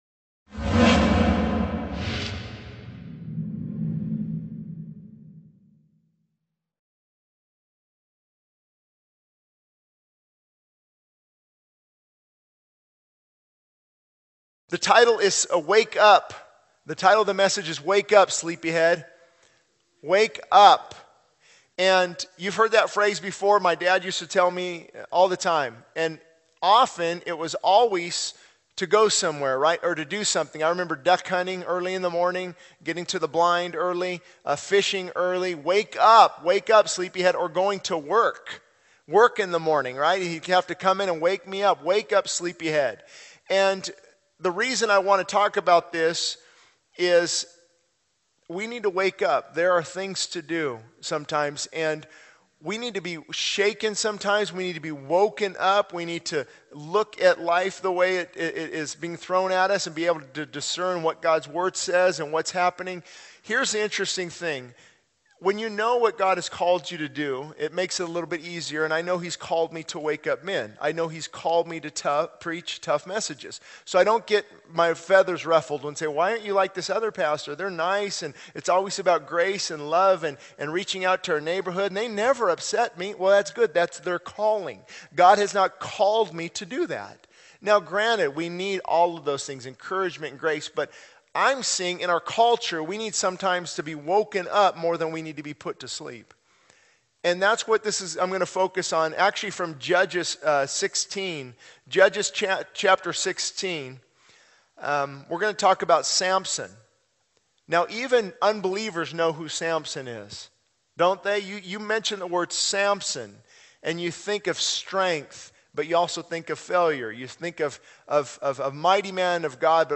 This sermon emphasizes the importance of waking up spiritually and being alert to the challenges and distractions that can lead us astray.